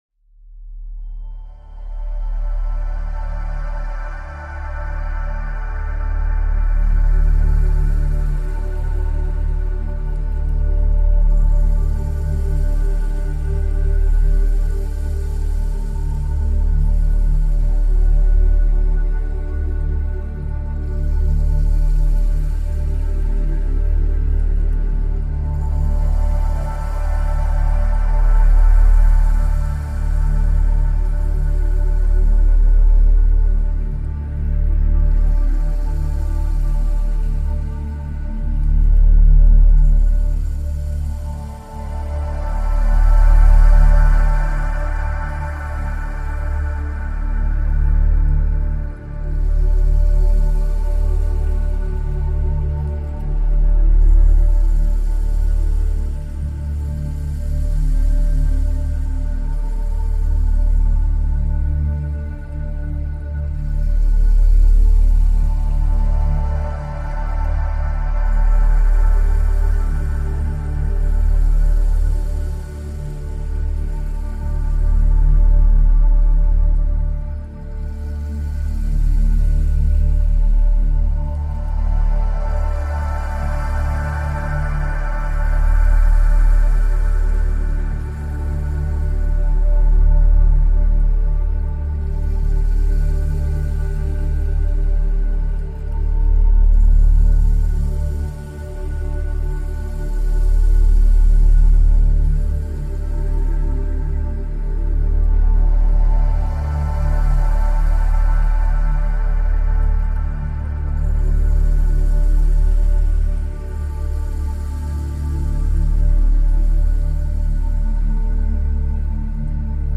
Calming Wind in Pine Forest for Mental Reset – Nature's Whisper for Gentle Concentration to Calm the Nervous System for Focus and Sleep to Calm the Nervous System
Each episode of Send Me to Sleep features soothing soundscapes and calming melodies, expertly crafted to melt away the day's tension and invite a peaceful night's rest.